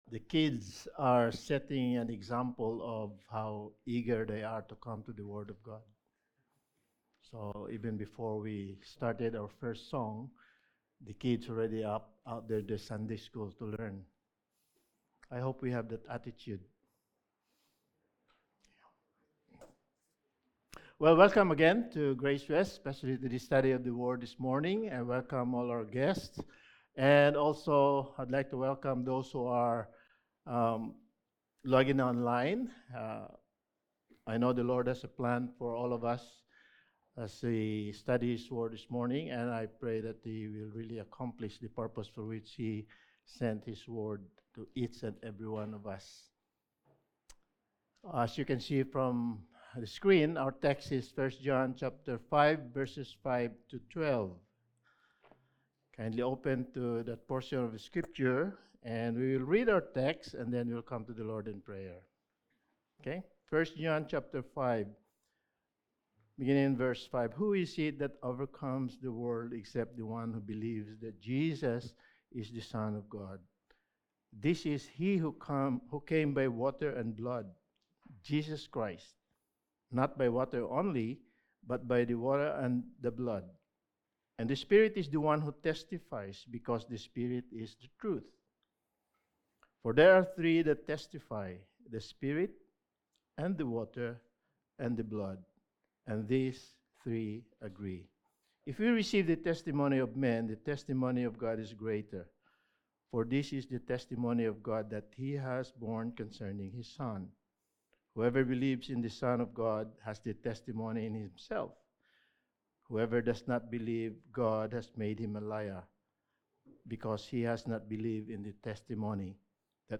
1 John Series – Sermon 16: God’s Witness for Christ
Service Type: Sunday Morning